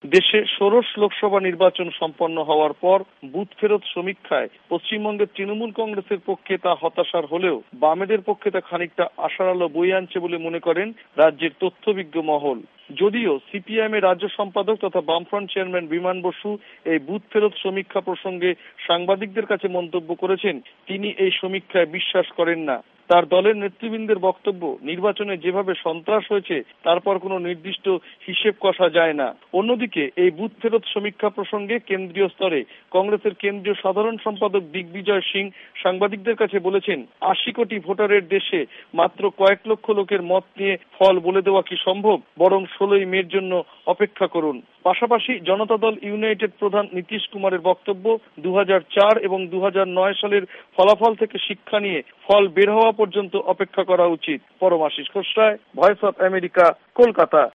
কলকাতা সংবাদদাতাদের রিপোর্ট